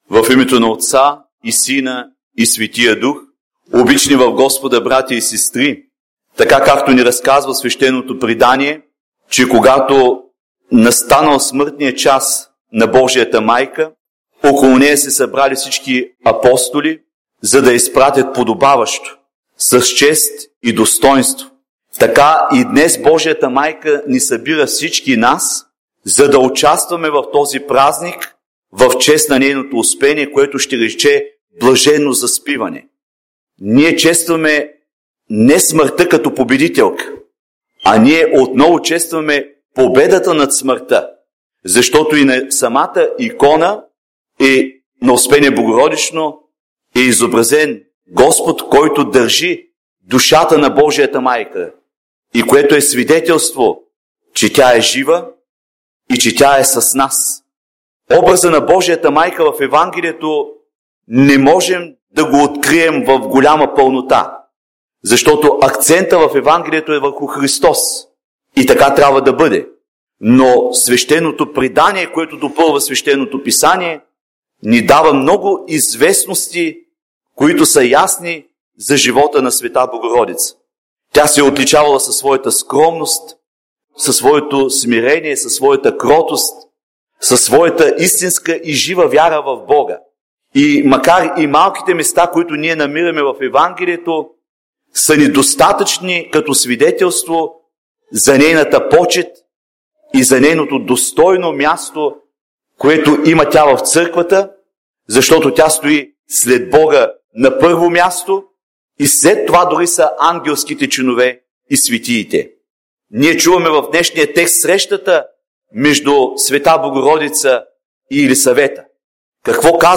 8 Неделя след Петдесетница – Успение на Пресвета Богородица – Проповед